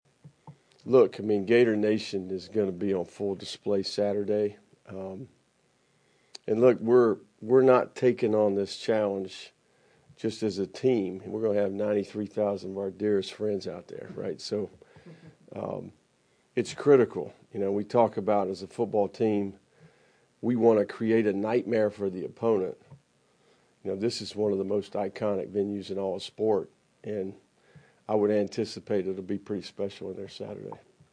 Florida football coach Billy Napier previewed the Week 1 matchup against No. 19 Miami during a news conference Monday.